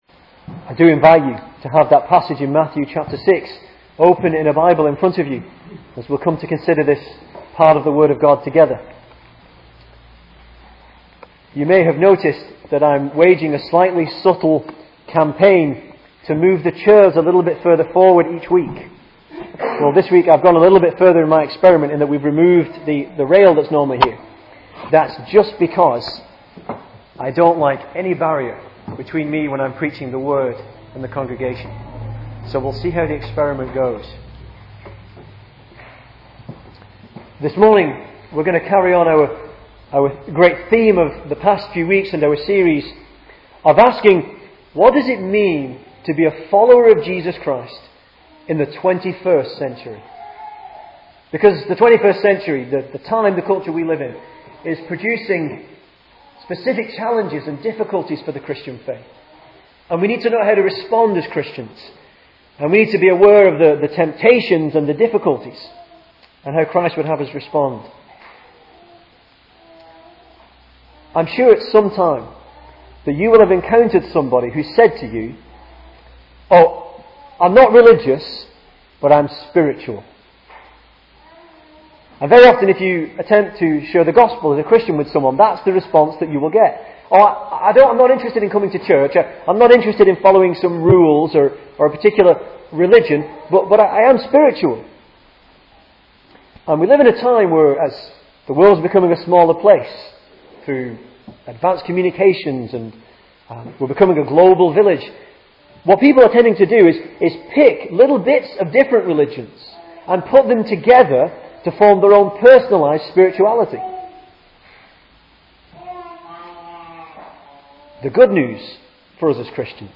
2010 Service Type: Sunday Morning Speaker